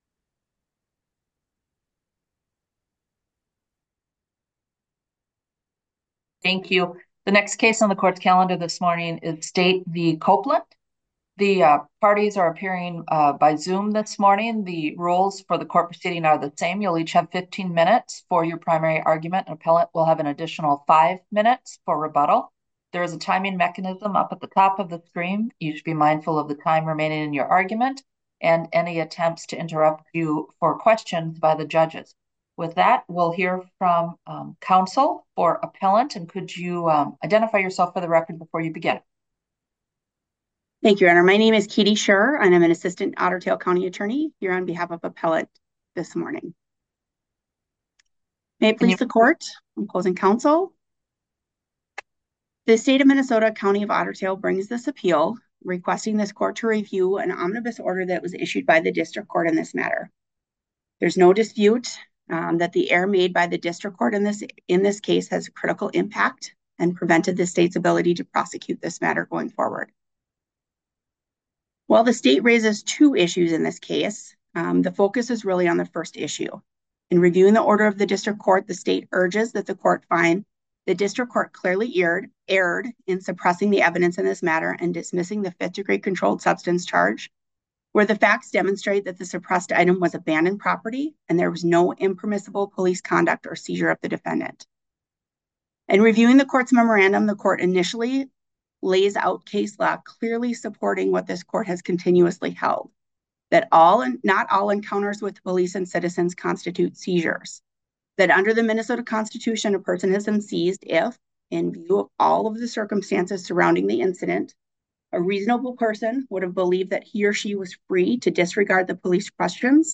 Minnesota Court of Appeals Oral Argument Audio Recording